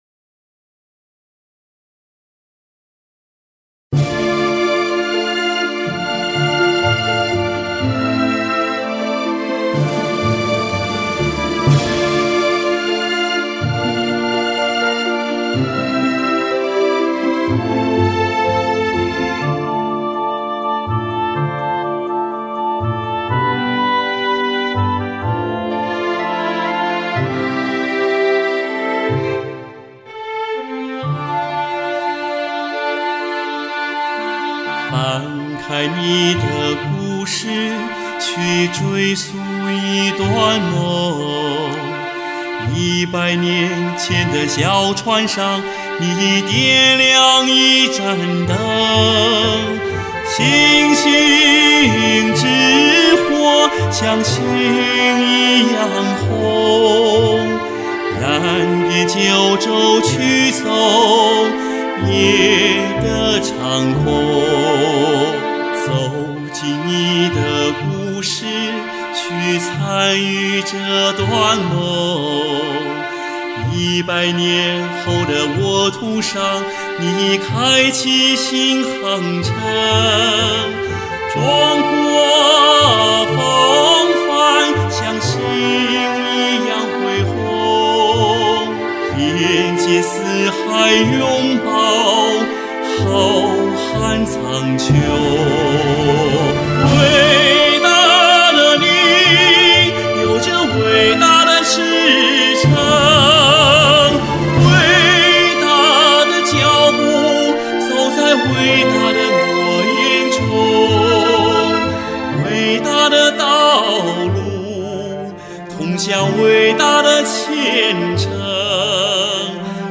今年是中国共产党成立100周年，正值学院第三次党员代表大会召开之际，艺术系教师创作并演唱歌曲《百年畅想》《心曲》《伟大的故事》，用作品诠释对党史的理解和感悟，用歌声为党代会献上最真诚的祝福。